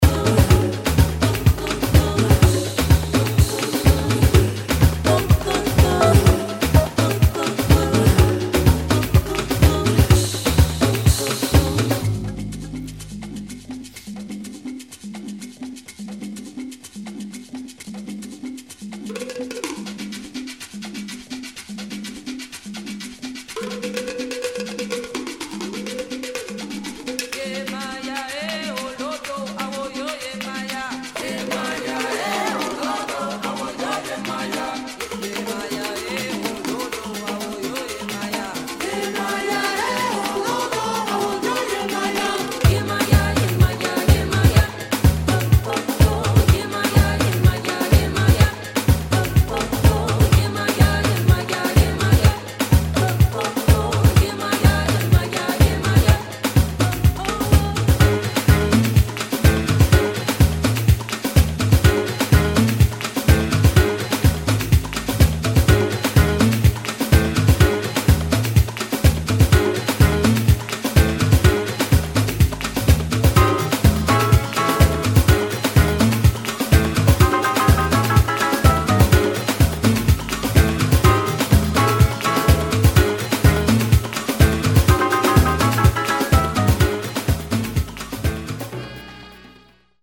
[ JAZZ / AFRO / CUBAN ]